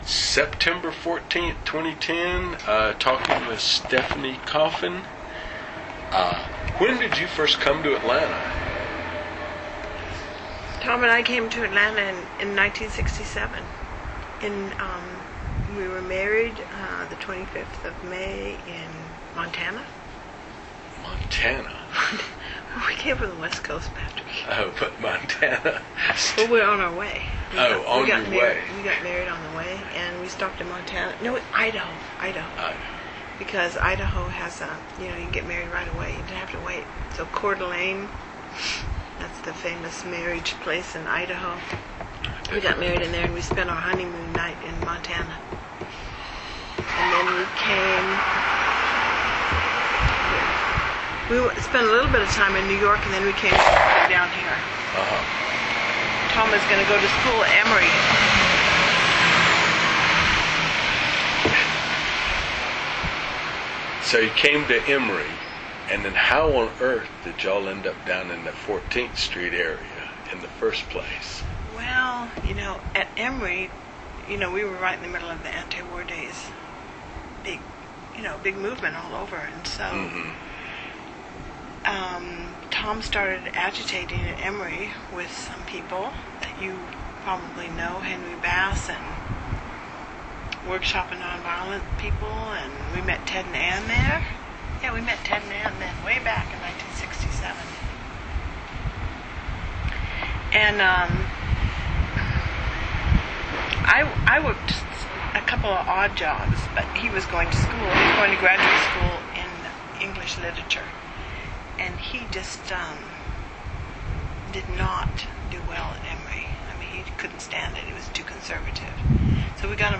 Bird People, interview